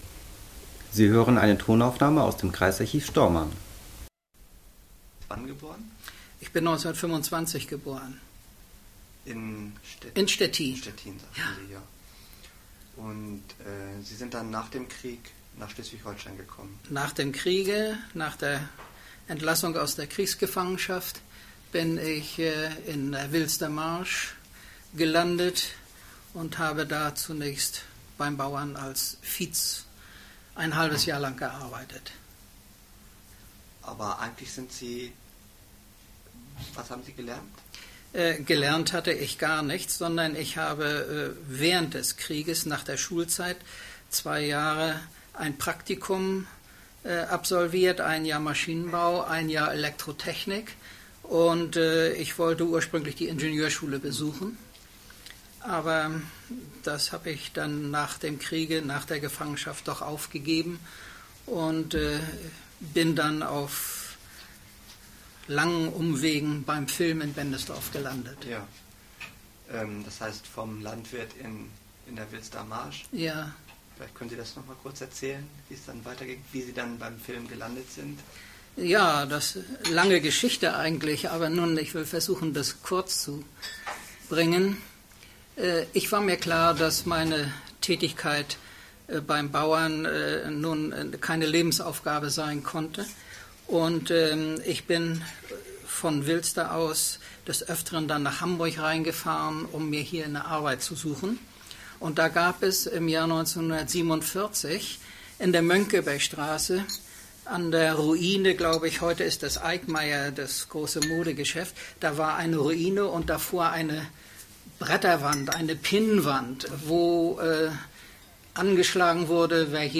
Tonkassette